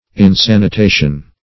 Meaning of insanitation. insanitation synonyms, pronunciation, spelling and more from Free Dictionary.
Search Result for " insanitation" : The Collaborative International Dictionary of English v.0.48: Insanitation \In*san`i*ta"tion\, n. Lack of sanitation; careless or dangerous hygienic conditions.